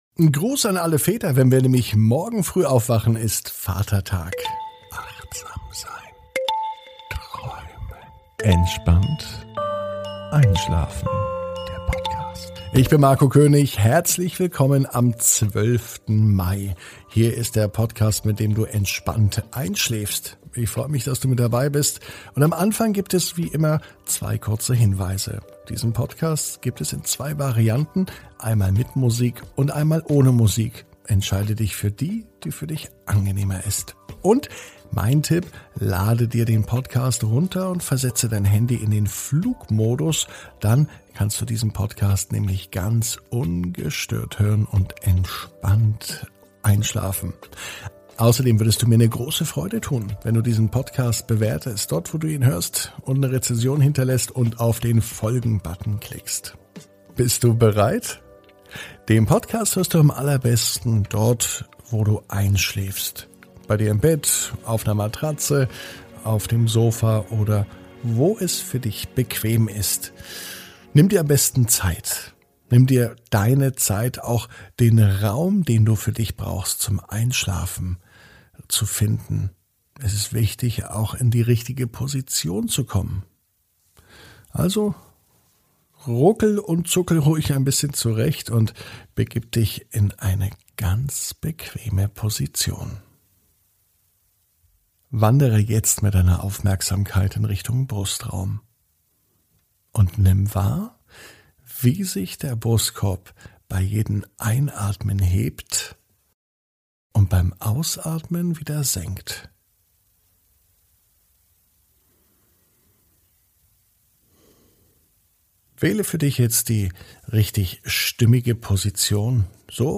(ohne Musik) Entspannt einschlafen am Mittwoch, 12.05.21 ~ Entspannt einschlafen - Meditation & Achtsamkeit für die Nacht Podcast